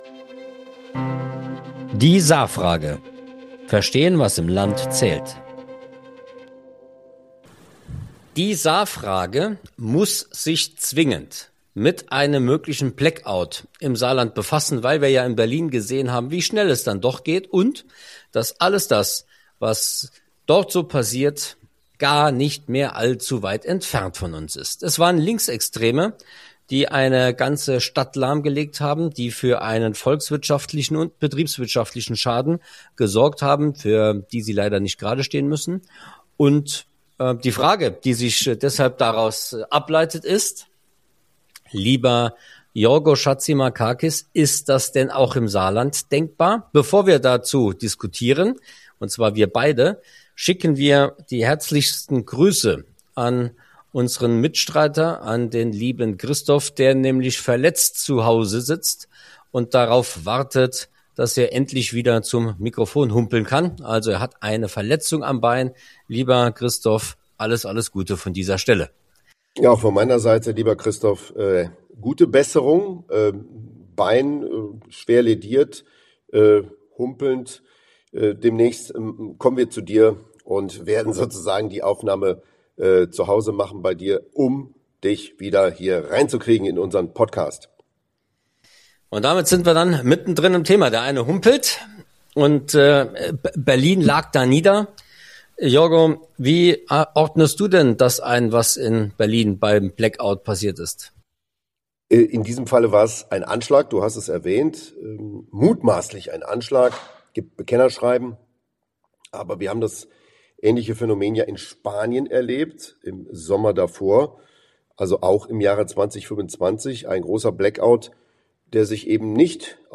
Und wie gut sind Wirtschaft, Politik und Infrastruktur tatsächlich auf Krisen vorbereitet? In dieser Folge von Die Saarfrage diskutieren